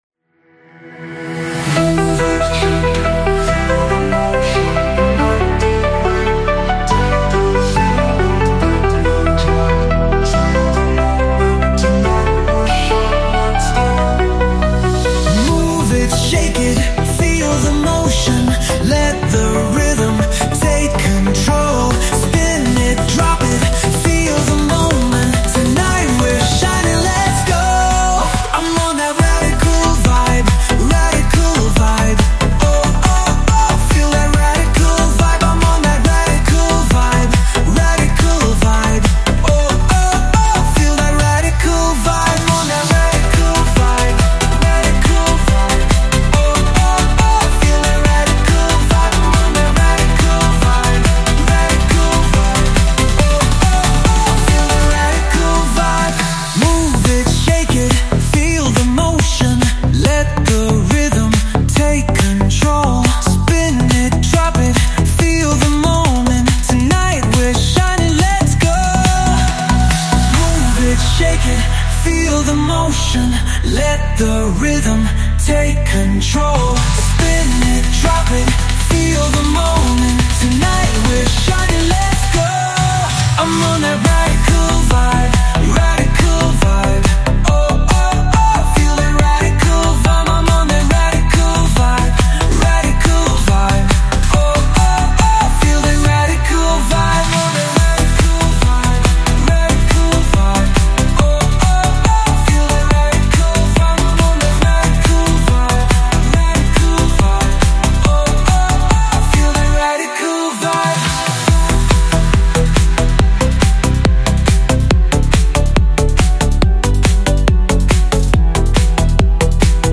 ELETRÔNICAS